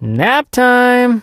sandy_kill_vo_01.ogg